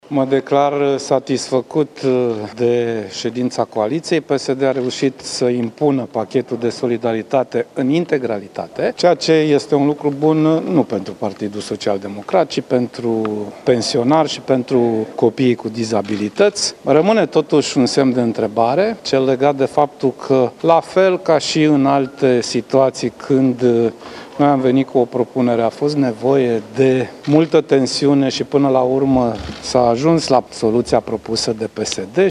La finalul discuțiilor de la Parlament cu liderii partidelor aflate la guvernare, președintele PSD, Sorin Grindeanu s-a plâns că au existat tensiuni.
Președintele PSD, Sorin Grindeanu: „PSD a reuşit să impună pachetul de solidaritate în integralitate”